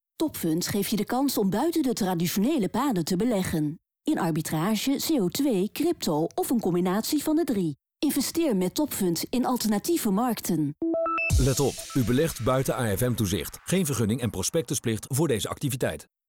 Daarom hebben we naast de tv commercial ook een radio commercial geproduceerd met dezelfde tone-of-voice.
Radiocommercial